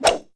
wrench_swipe2.wav